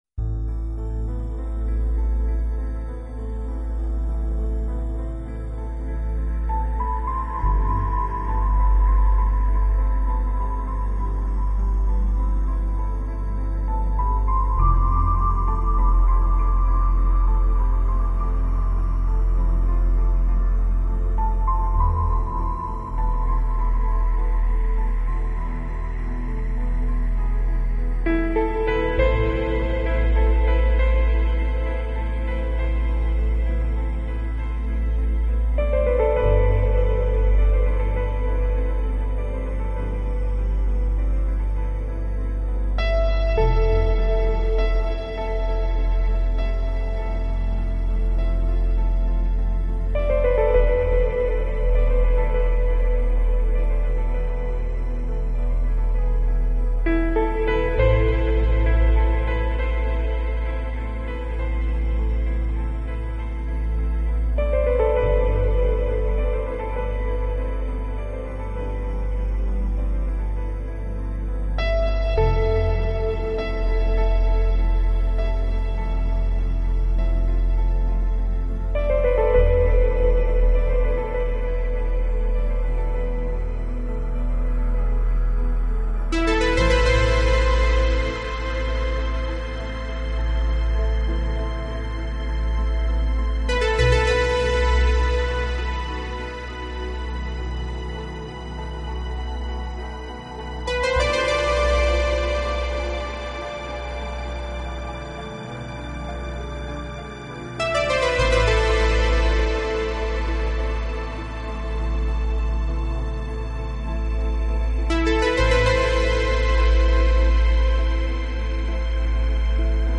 音乐风格：New Age/Electronic
豪华且繁杂的编曲手法；感性的的钢琴音乐旋律。